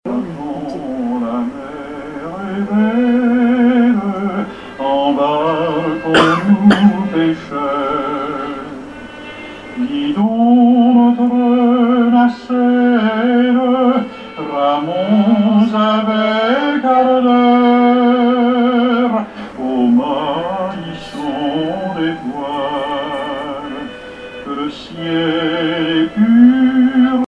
Informateur(s) Club d'anciens de Saint-Pierre association
Genre strophique
Pièce musicale inédite